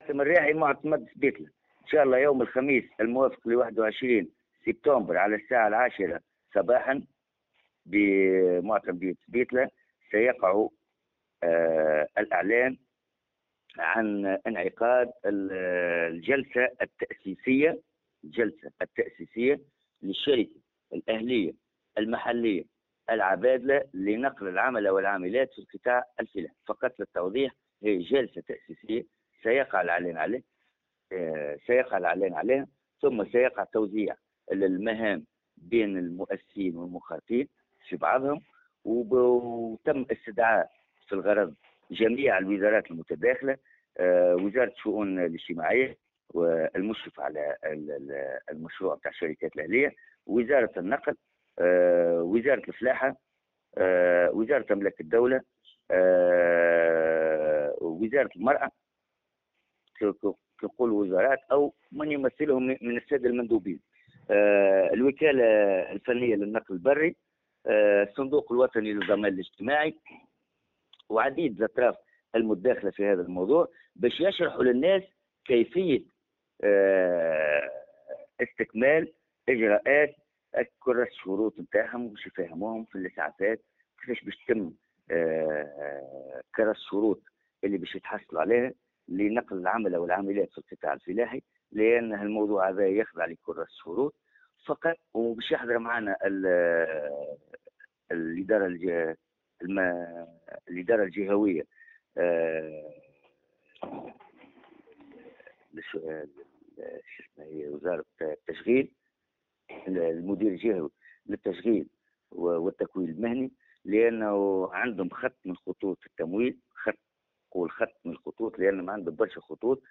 Le délégué de Sbeitka, Hatem Riahi, a déclaré aujourd’hui à Tunisie Numérique qu’une entreprise citoyenne sera lancée jeudi prochain pour assurer le transport des ouvriers agricoles.